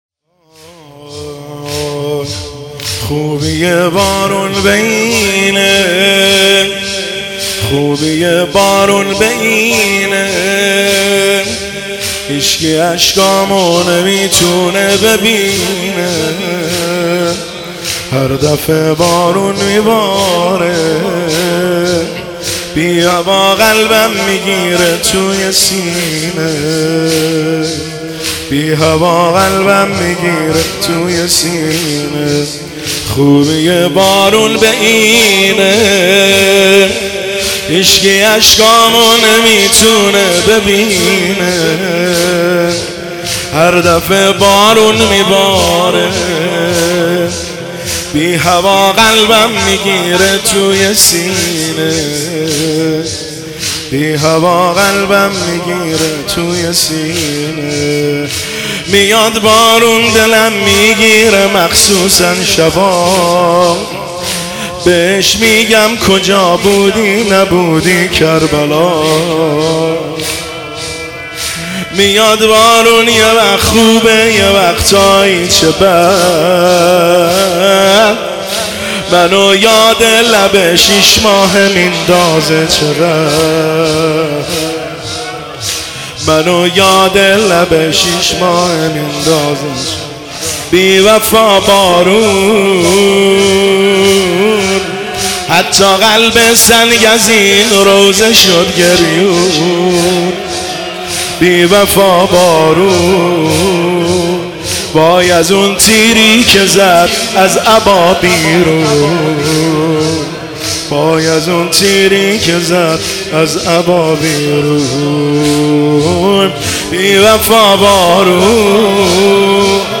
دانلود فایل صوتی مداحی زمینه احساسی روضه ای کربلا امام حسین
هیئت هفتگی